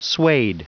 Prononciation du mot suede en anglais (fichier audio)
Prononciation du mot : suede